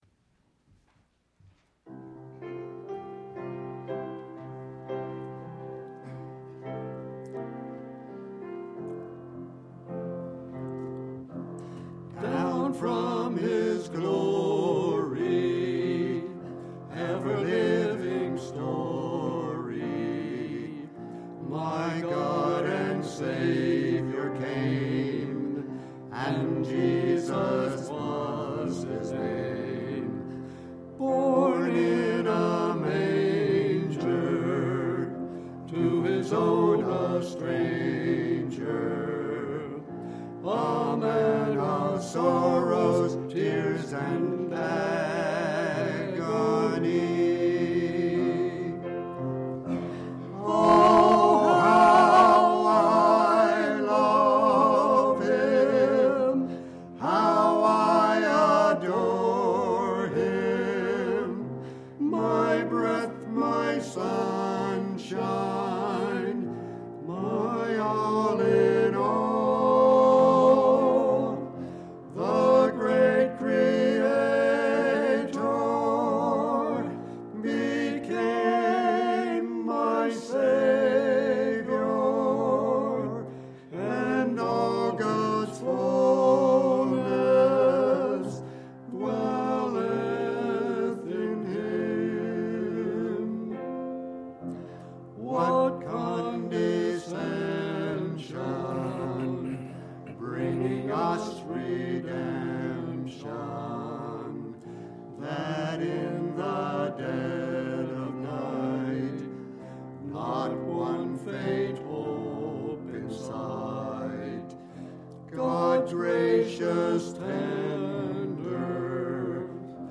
singing
NHBC Music Ministry